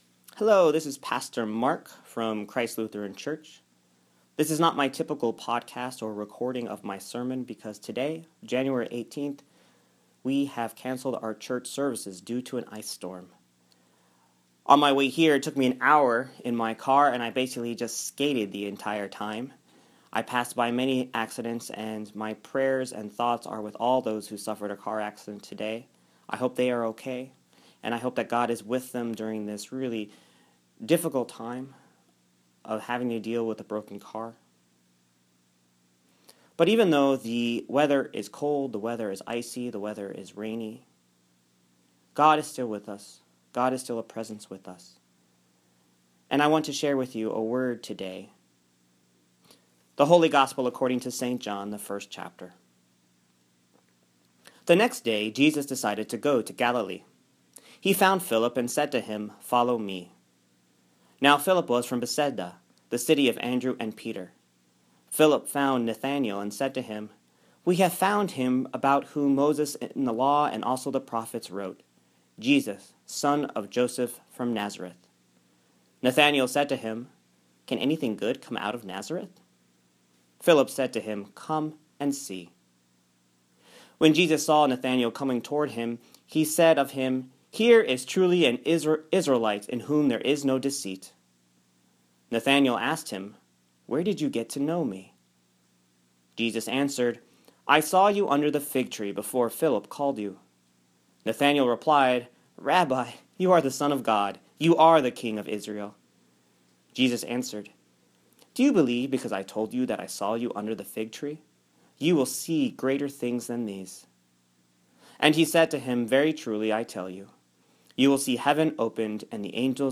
My sermon from the 2nd Sunday after Epiphany (January 18, 2015) on Luke 1:43-51. An ice storm canceled the church service but I recorded the sermon anyways.